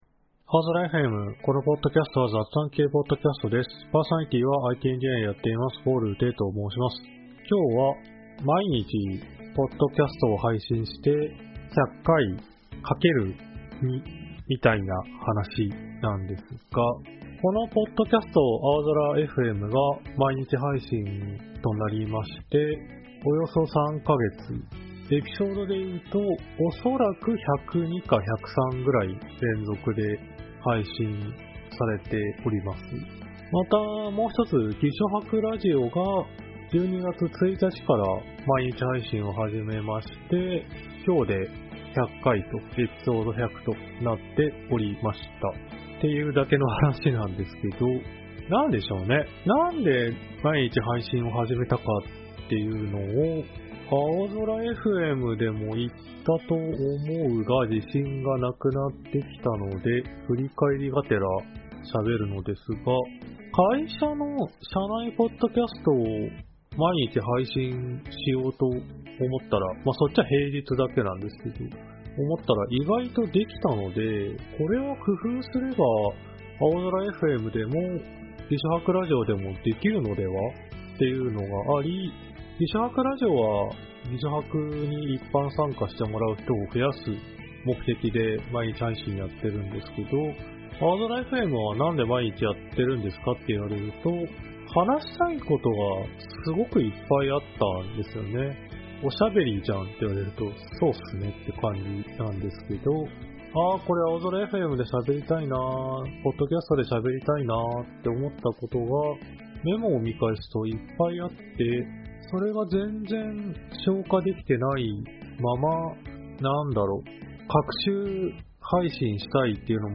aozora.fmは仕事や趣味の楽しさを共有する雑談系Podcastです。